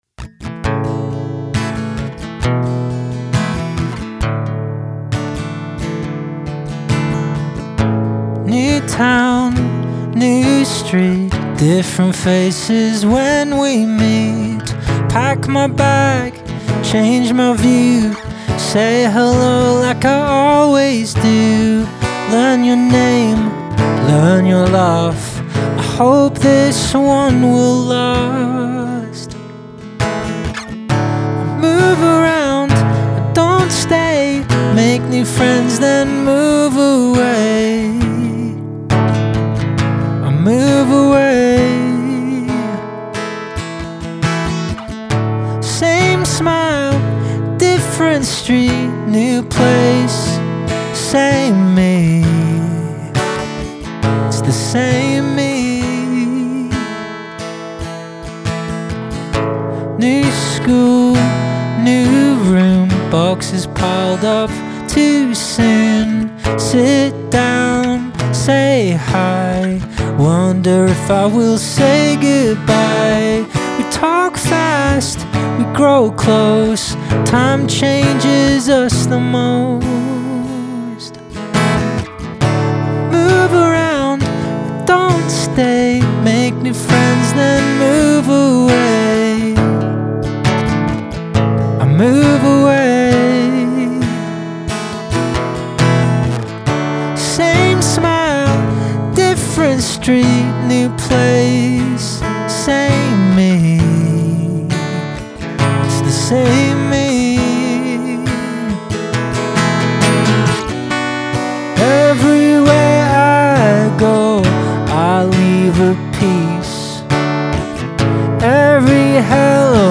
Written by Service children at the North East Wales Armed Forces Festival (November 2025).